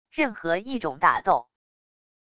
任何一种打斗wav音频